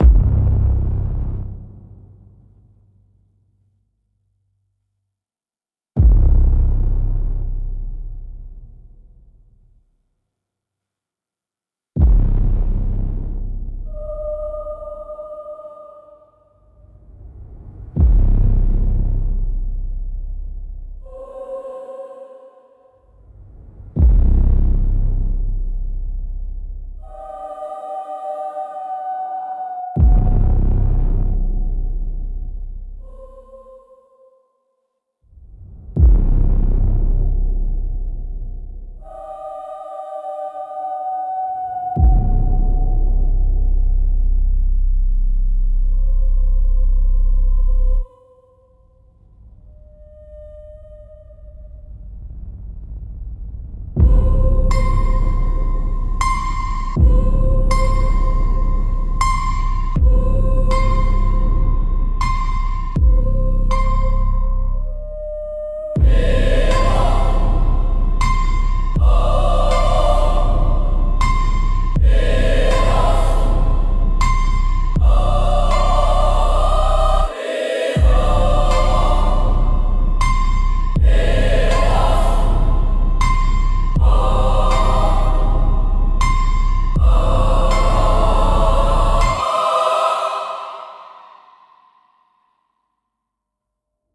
via Suno AI Music
Once Gone Trailer Track